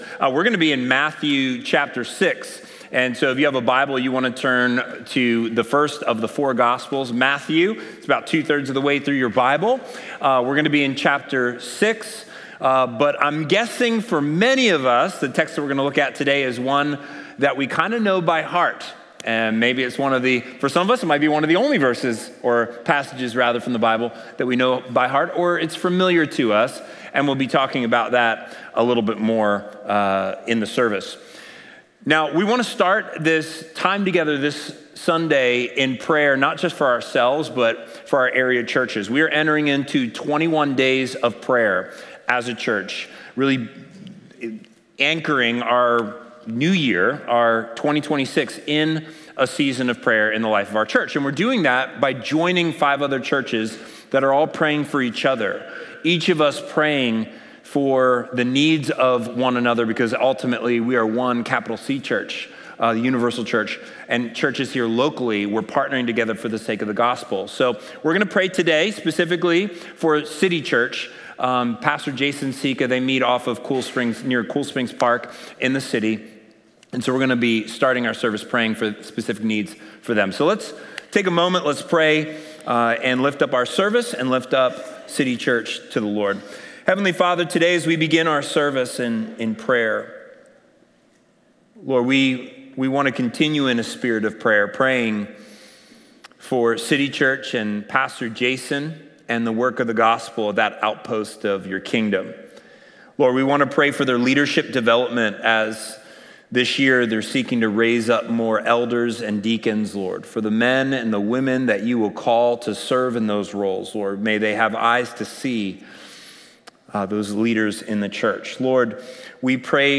Watch previously recorded Sunday sermons.